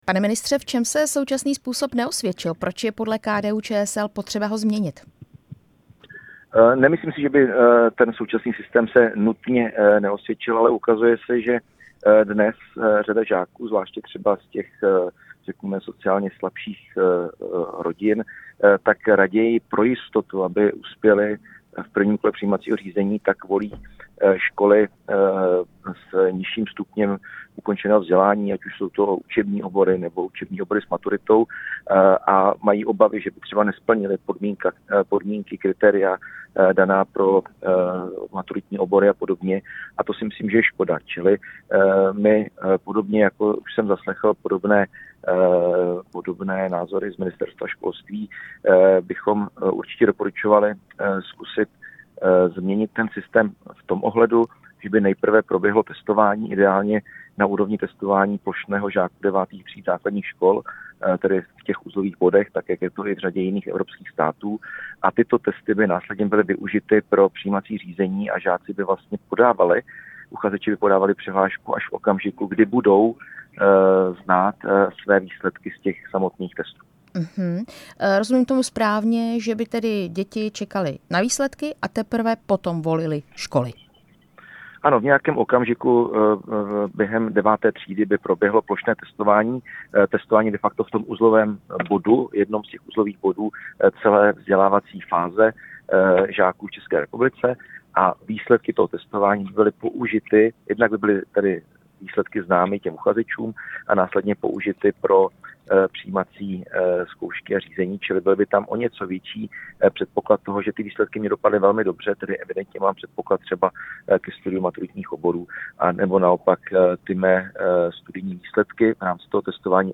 Byl hostem Rádia Prostor.
Rozhovor s předsedou KDU-ČSL Markem Výborným